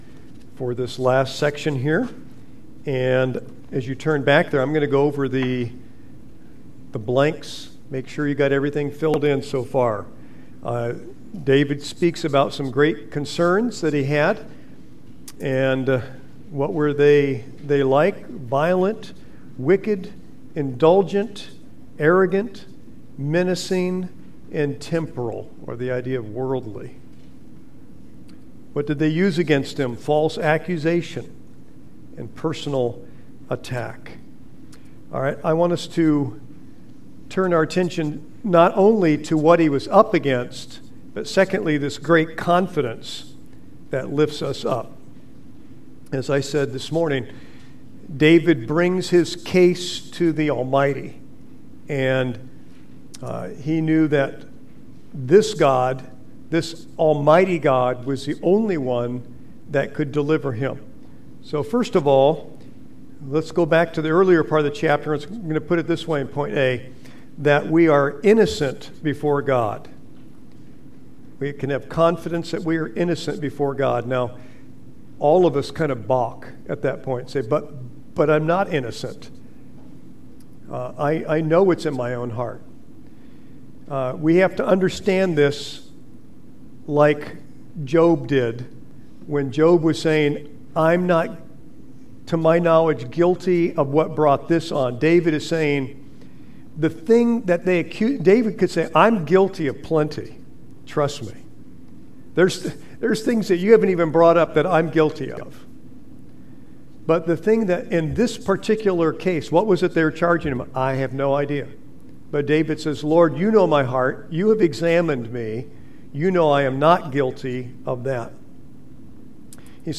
Service Sunday Evening